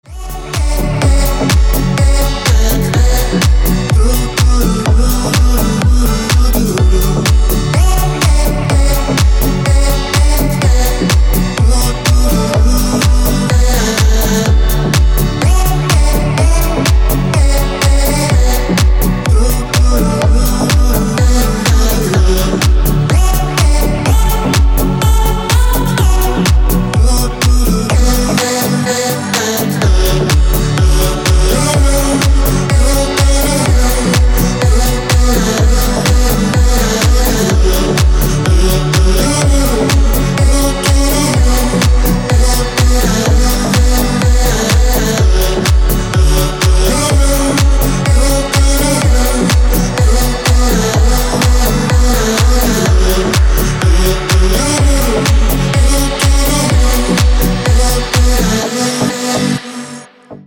dance
электронная музыка
спокойные
без слов
club